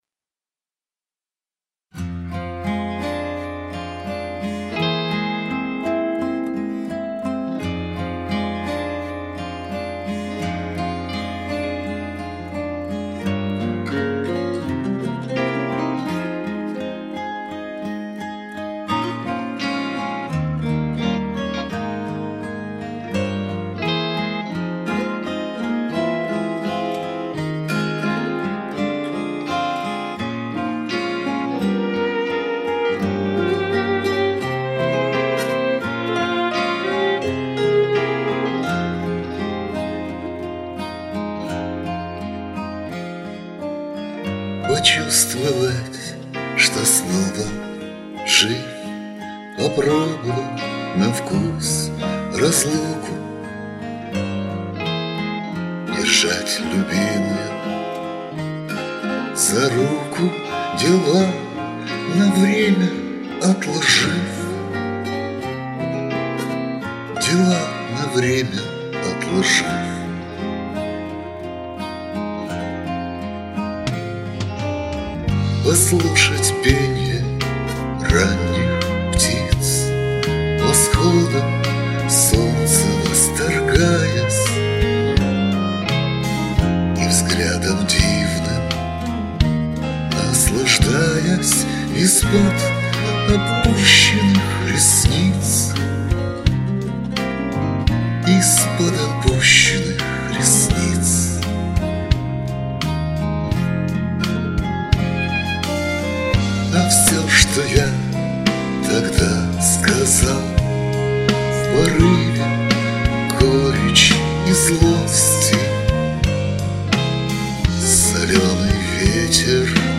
• Жанр: Романс